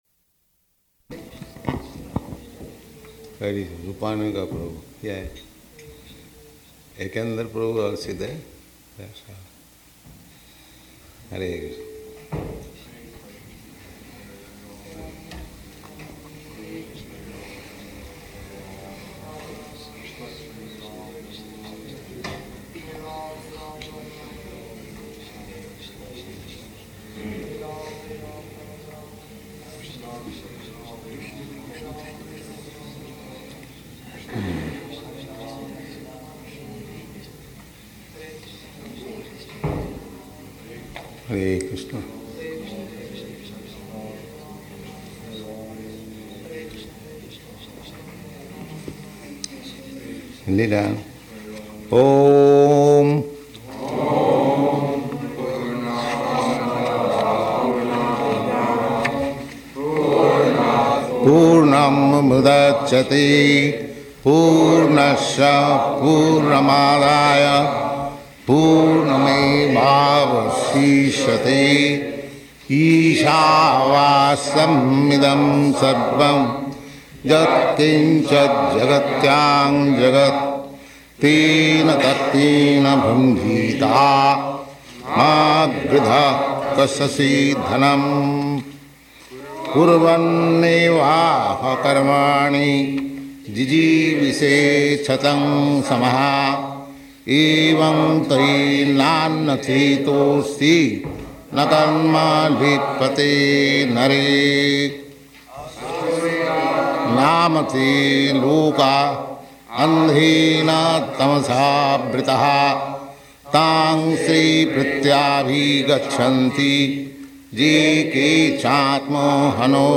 May 9th 1970 Location: Los Angeles Audio file